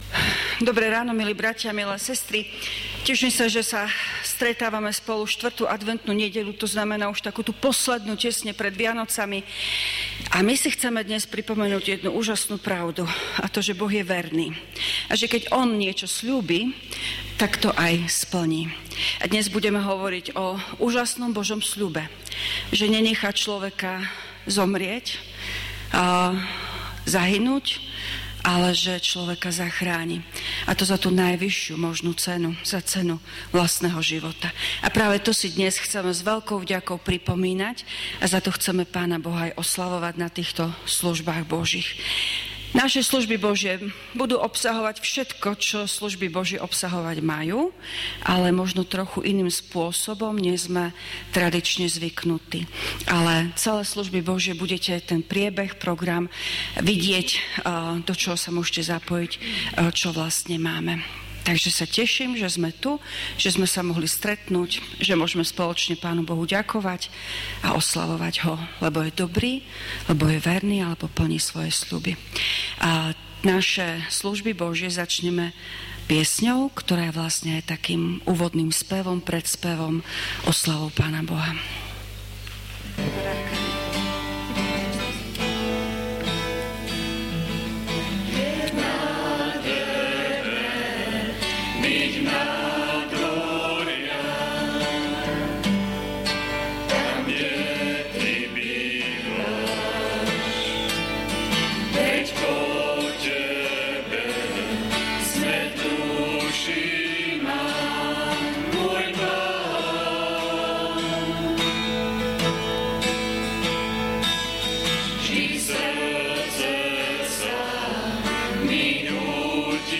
V nasledovnom článku si môžete vypočuť zvukový záznam zo služieb Božích – 4. adventná nedeľa – Vianočná akadémia.